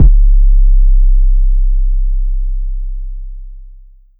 ATL 808.wav